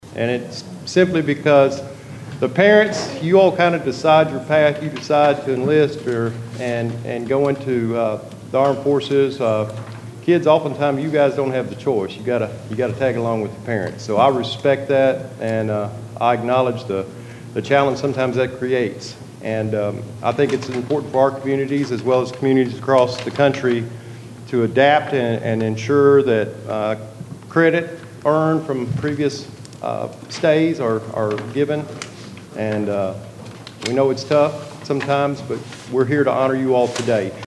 Christian County Fiscal Court opened Tuesday’s meeting celebrating “Month of the Military Child,” while proclaiming Wednesday, April 15, a “Purple Up Day” across the community.